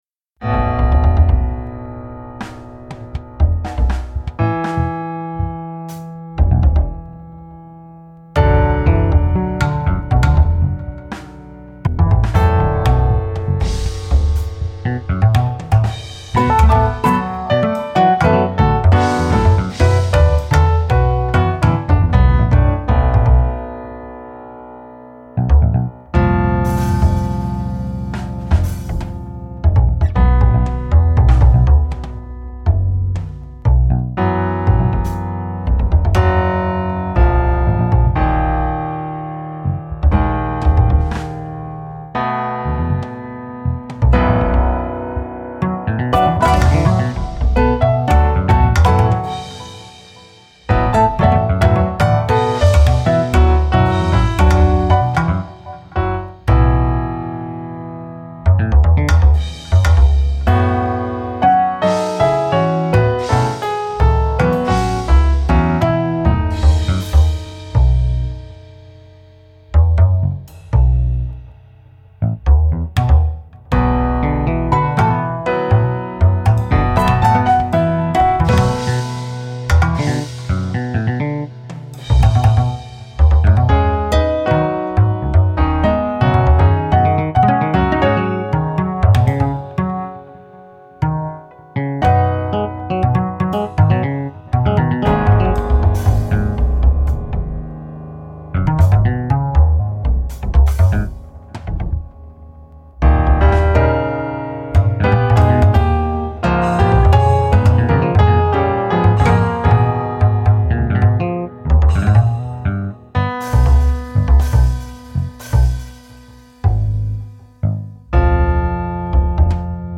improv jazz session.
impressing improv – must be really big fun to play with the matrix